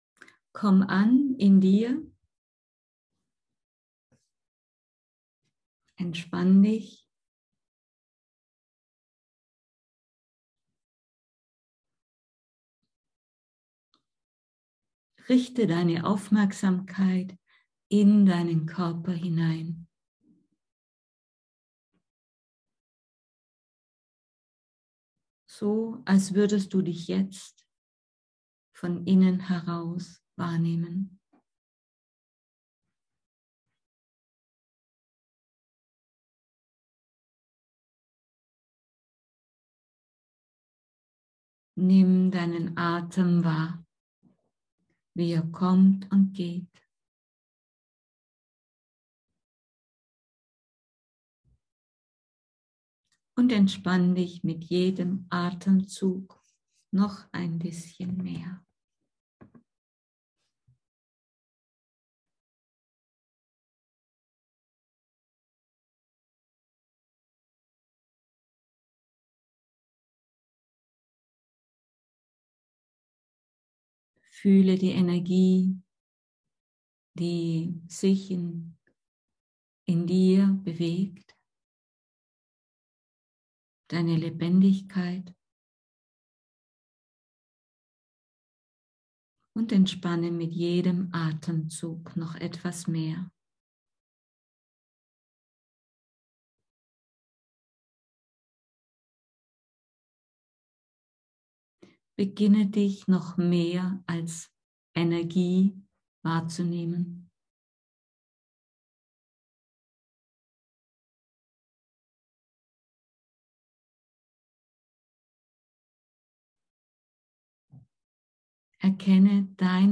Audio Datei Meditation: Lichtfunken